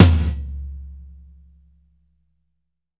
Index of /90_sSampleCDs/Sampleheads - New York City Drumworks VOL-1/Partition A/KD TOMS
FLOORGATE2-L.wav